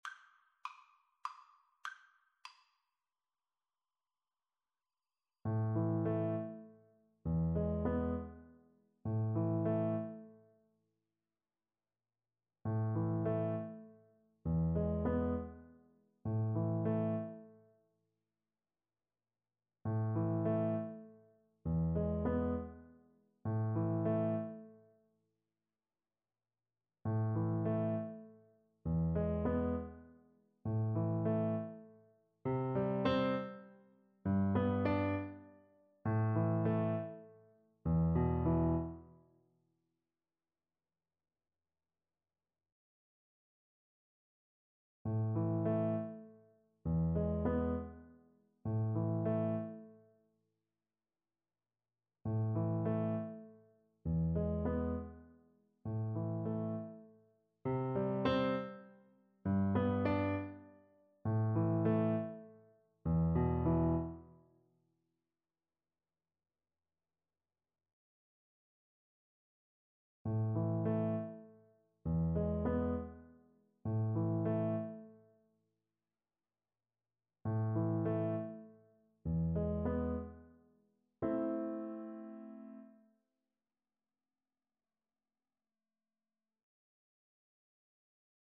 Free Sheet music for Piano Four Hands (Piano Duet)
A minor (Sounding Pitch) (View more A minor Music for Piano Duet )
=150 Moderato
Classical (View more Classical Piano Duet Music)